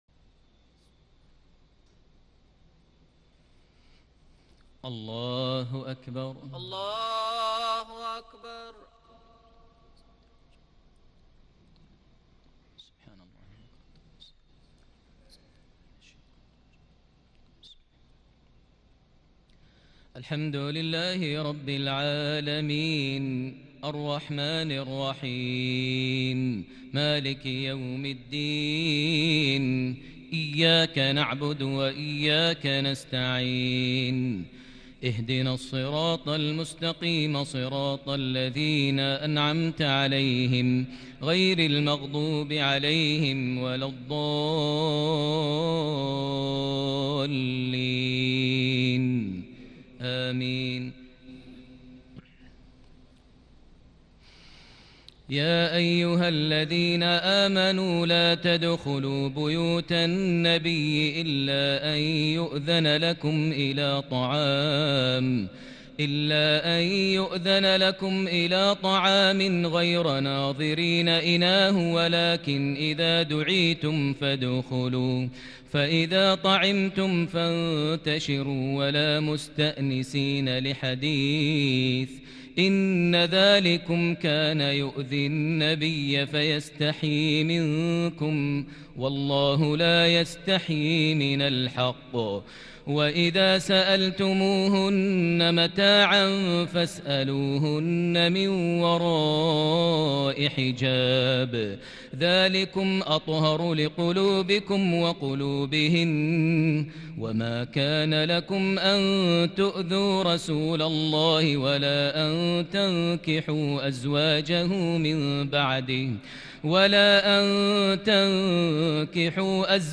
تهجد ليلة 24 رمضان 1441هـ من سورتي الأحزاب 53_ سبأ 1-23 | Tahajjud 24 st night Ramadan 1441H Surah Al-Ahzaab and Saba > تراويح الحرم المكي عام 1441 🕋 > التراويح - تلاوات الحرمين